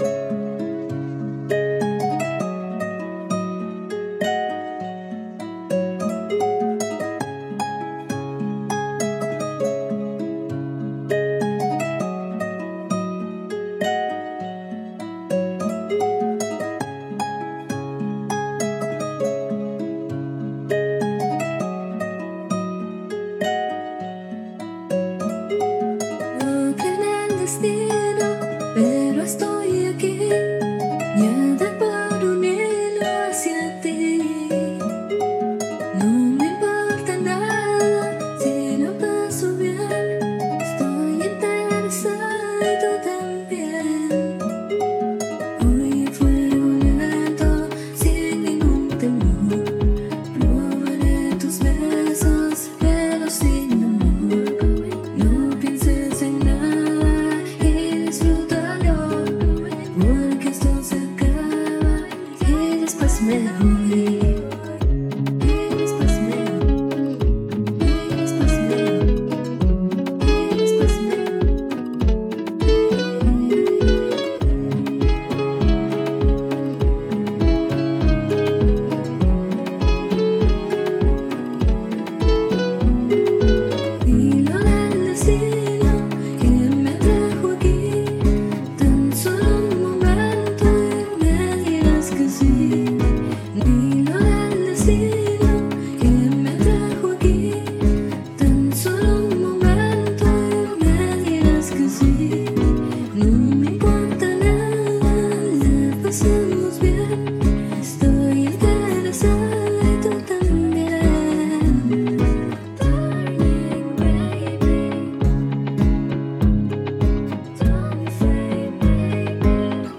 guitare, du piano, de la batterie et de la flûte